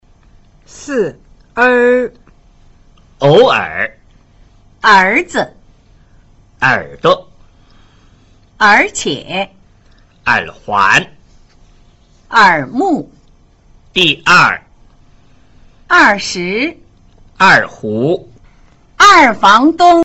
它的發音是在舌位不高不低不前不後的央元音【 】的基礎上,同時帶有捲舌動作, 是舌尖和舌面同時起作用。